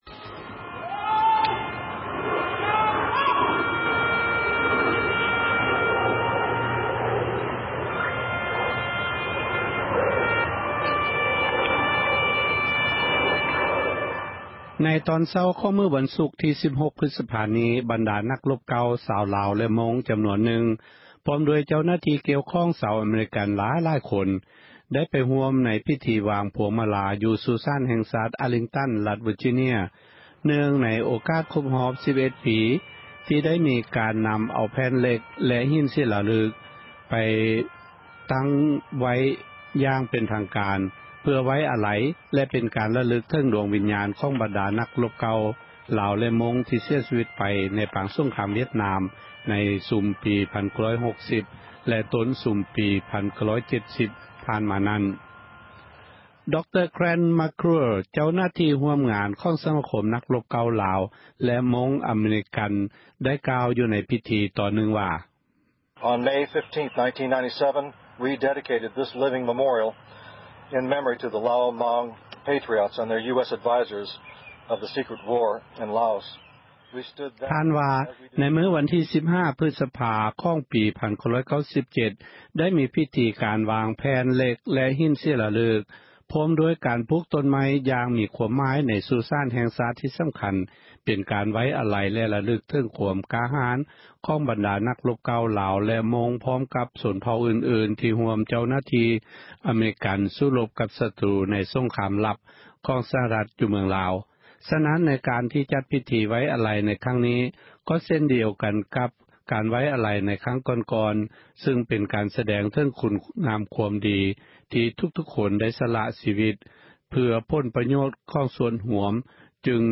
ພິທີ ວາງພວງມະລາ ຢູ່ ສຸສານ ແຫ່ງຊາຕ ທີ່ “ອາຣ໌ລິງຕັ໋ນ” ເພື່ອ ໄວ້ອາລັຍແລະ ຣະລຶກ ເຖິງ ດວງວິນຍານ ຂອງ ບັນດາ ນັກຣົບລາວ ແລະ ມົ້ງ ທີ່ ເສັຽຊີວີຕ ໃນປາງສົງຄາມ ວຽດນາມ.